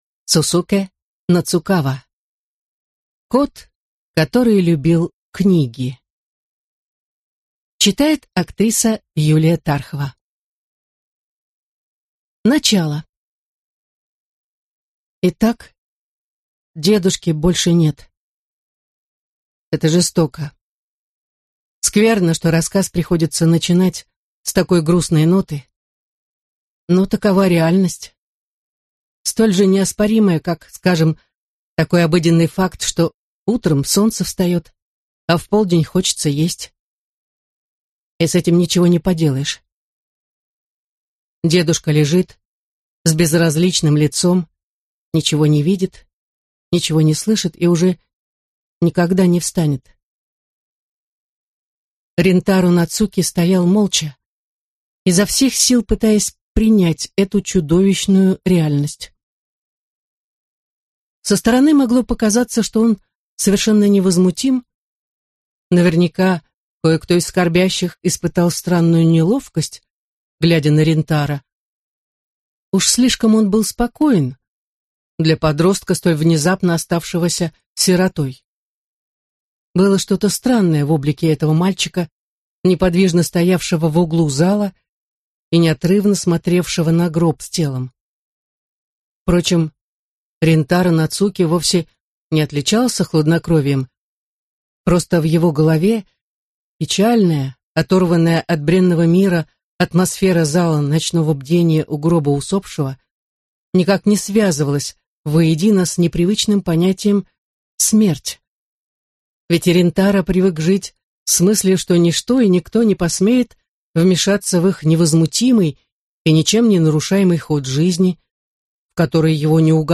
Аудиокнига Кот, который любил книги | Библиотека аудиокниг